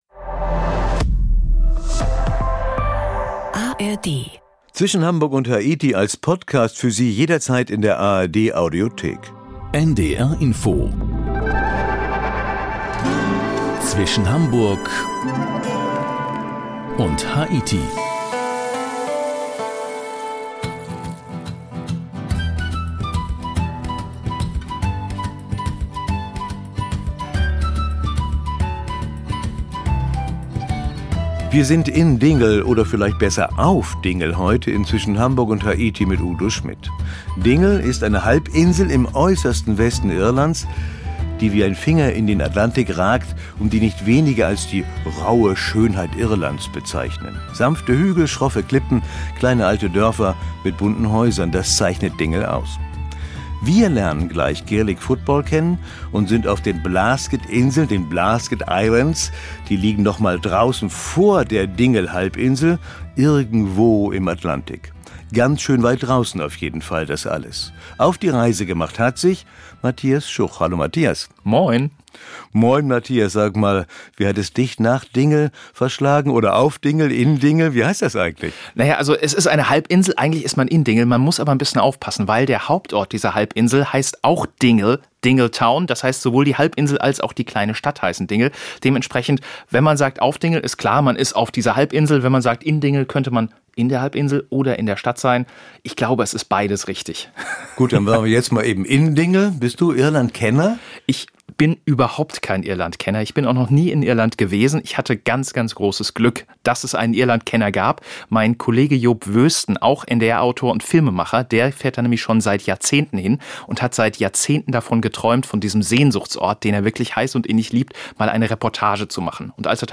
Mit dem Mikrofon rund um die Welt.